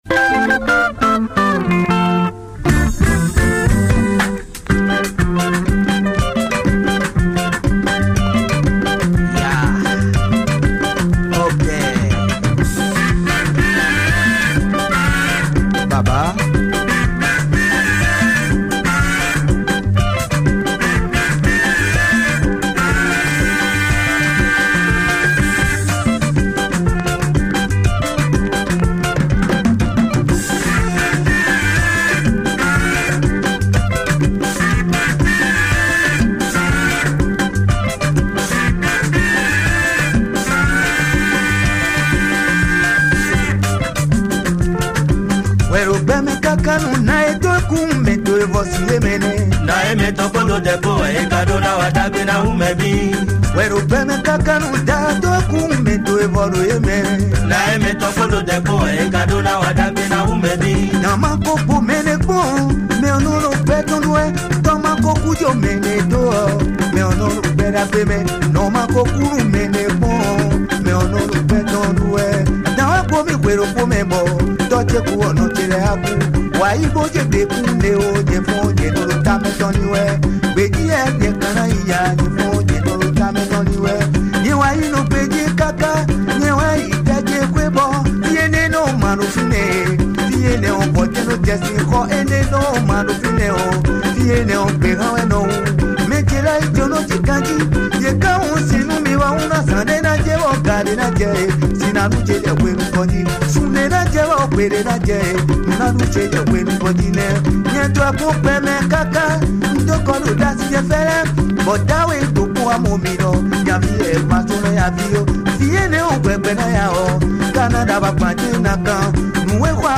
Afrobeat and afro groove